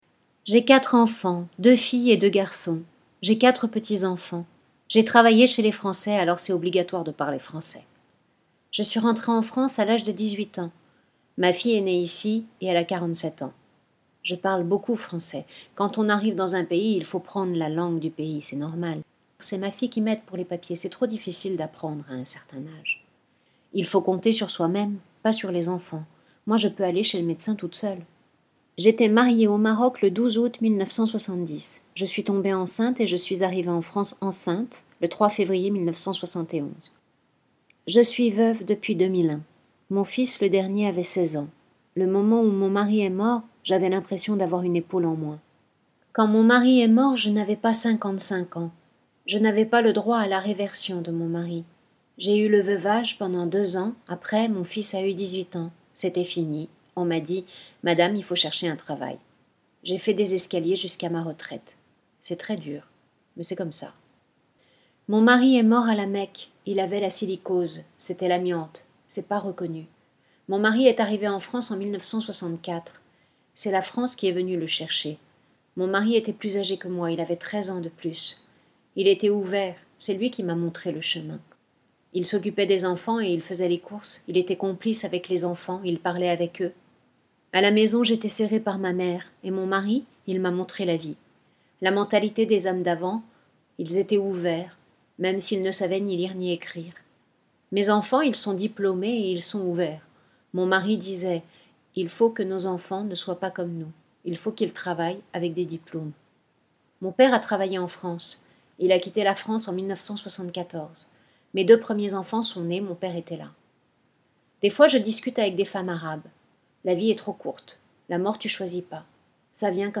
Je Vous Parle - Lecture Spectacle